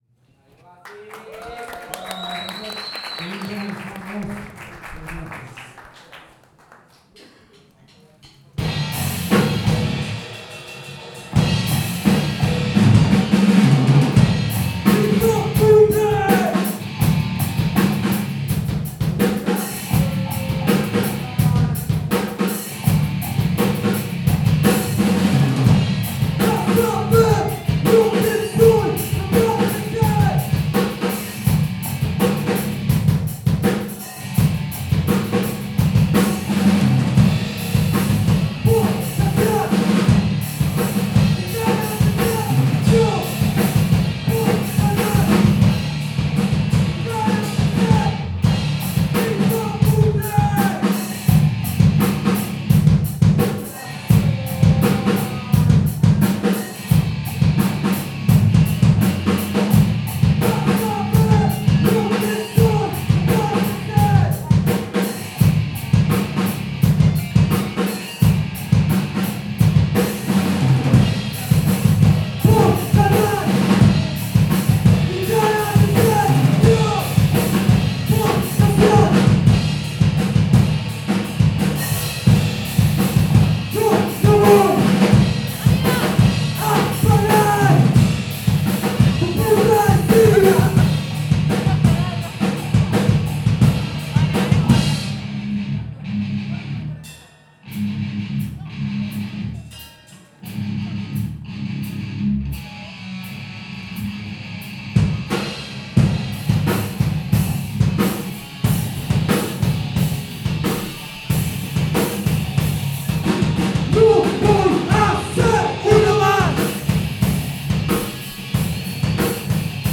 Sonido subterráneo